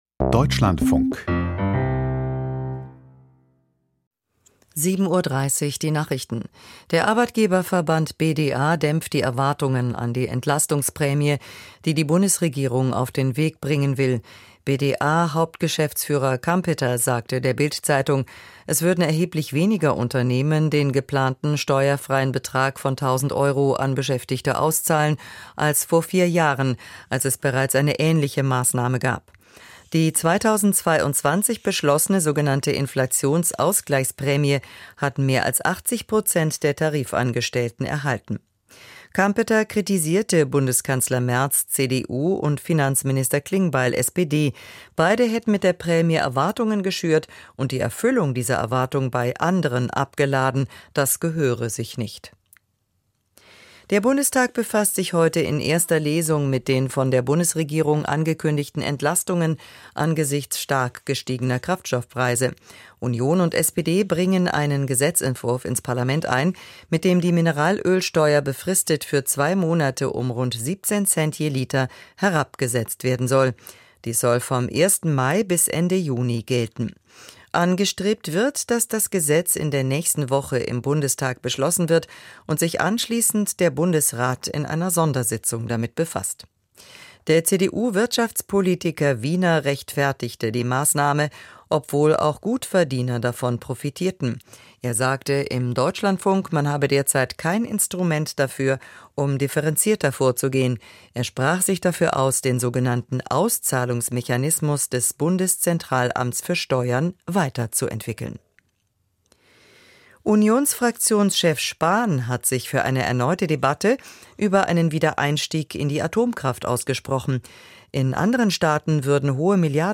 Die Nachrichten vom 16.04.2026, 07:30 Uhr